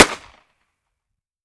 38 SPL Revolver - Gunshot A 002.wav